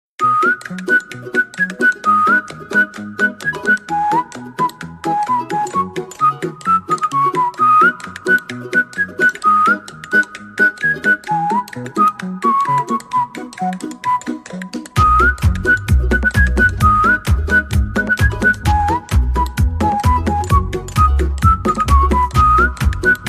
DOWELL DMPro 3d printer 100mm/s sound effects free download
DOWELL DMPro 3d printer 100mm/s sound effects free download By sales.dowell3d 1 Downloads 13 months ago 23 seconds sales.dowell3d Sound Effects About DOWELL DMPro 3d printer 100mm/s Mp3 Sound Effect DOWELL DMPro 3d printer 100mm/s up to 250mm printing speed test.